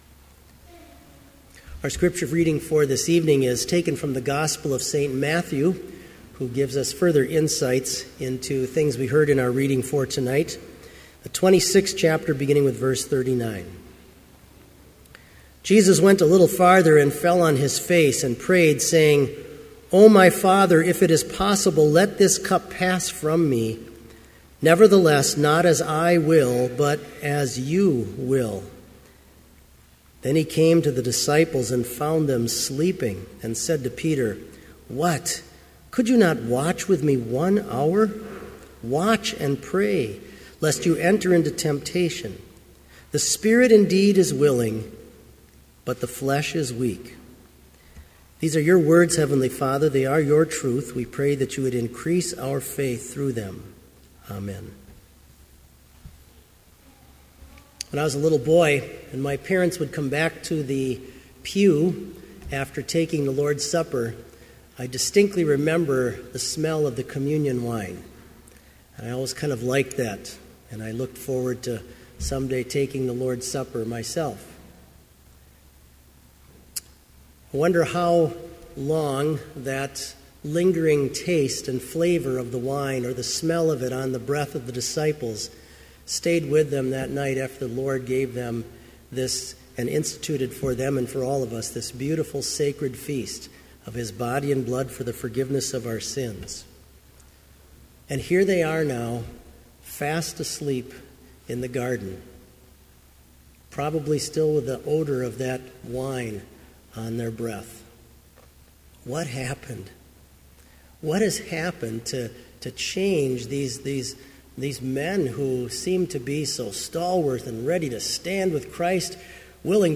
Sermon audio for Lenten Vespers - February 25, 2015